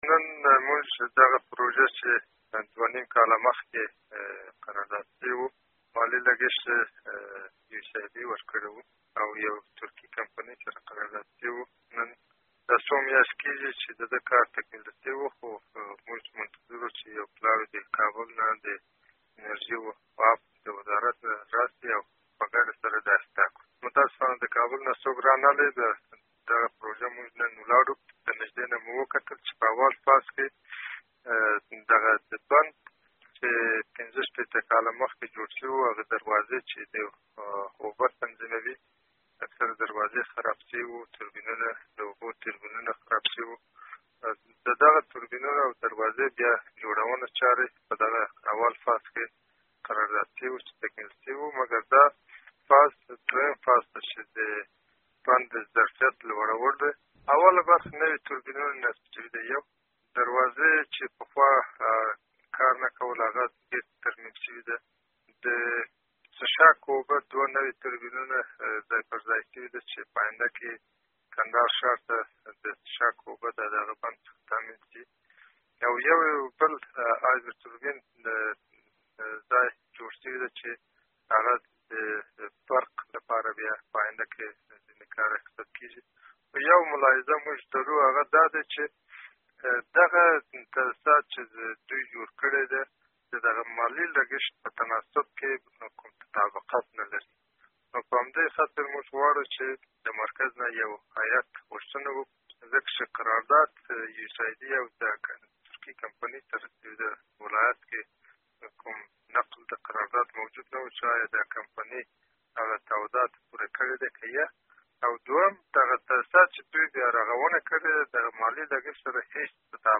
د کندهار والي ډاکټر همایون عزیزي سره مرکه: